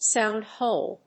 アクセントsóund hòle